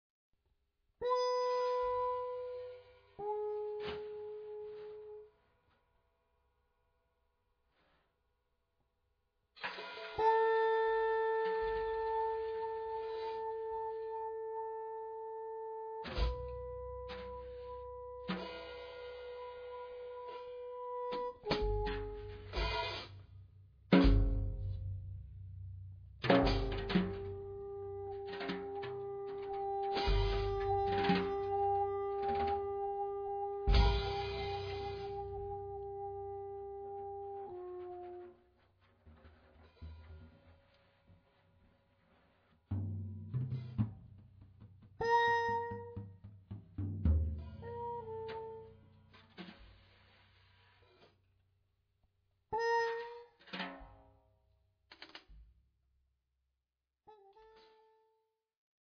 Jazz
free jazz to the core